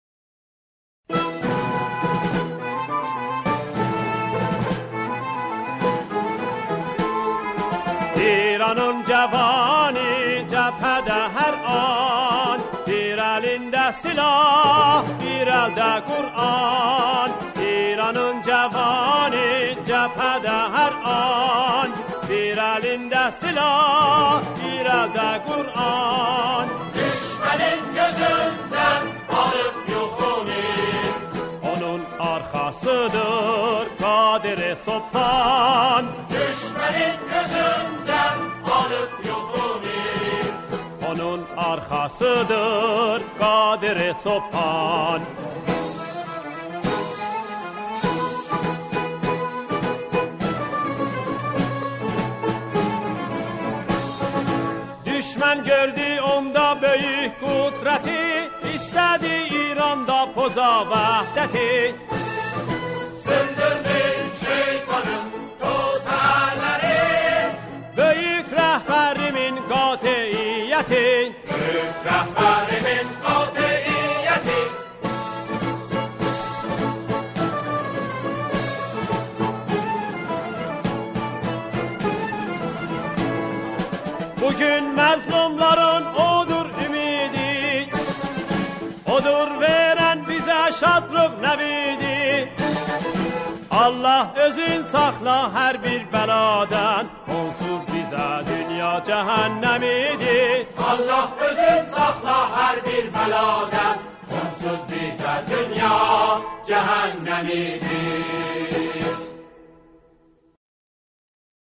سرودهای انگیزشی